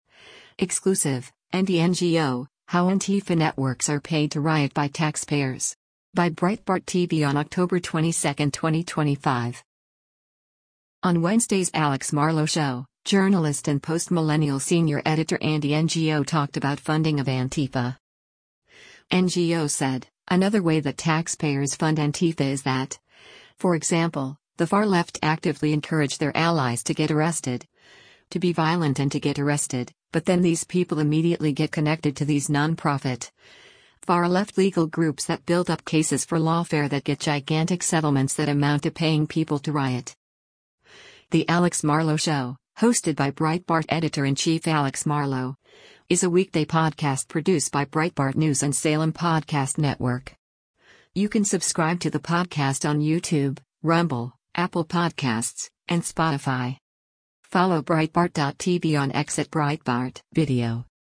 On Wednesday’s “Alex Marlow Show,” journalist and Post Millennial Senior Editor Andy Ngo talked about funding of Antifa.
“The Alex Marlow Show,” hosted by Breitbart Editor-in-Chief Alex Marlow, is a weekday podcast produced by Breitbart News and Salem Podcast Network.